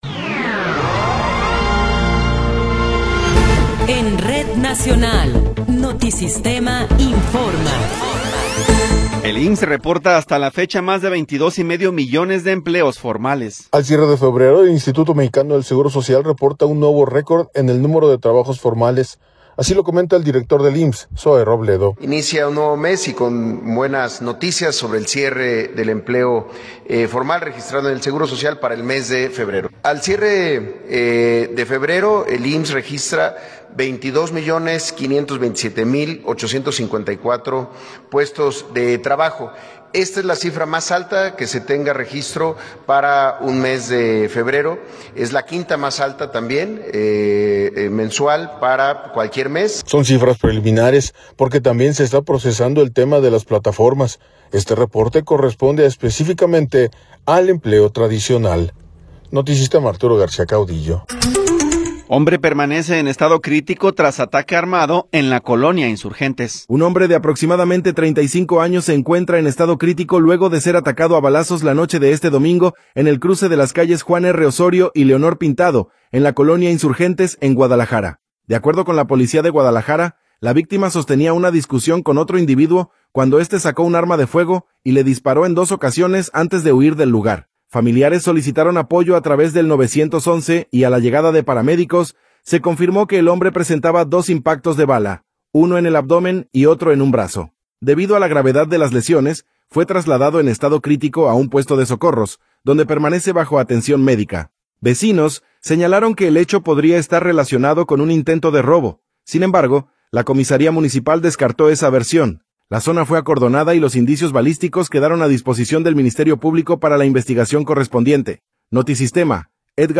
Noticiero 10 hrs. – 2 de Marzo de 2026